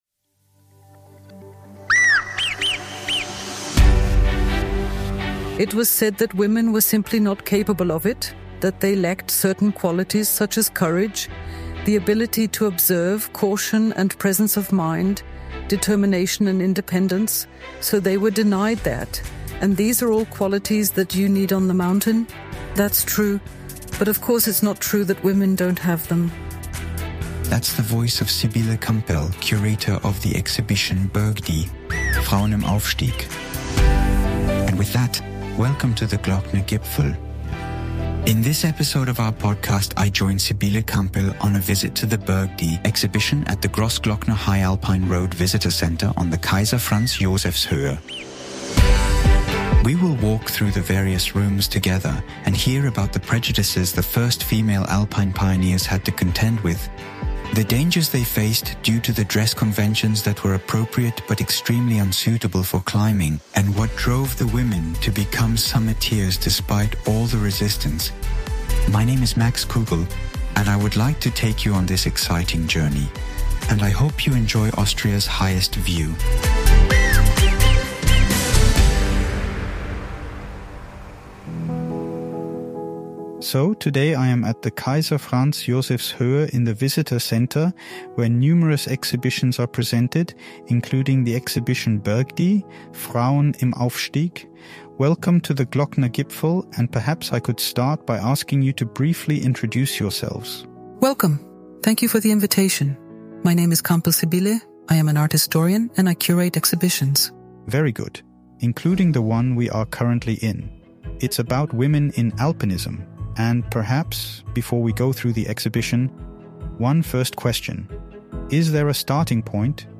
Together we will walk through the various rooms of the exhibition and hear what prejudices the first Alpine pioneers had to struggle with and what dangers they faced due to the clothing conventions that were appropriate for their status but unsuitable for climbing and what drove the women to become summiteers despite all the resistance.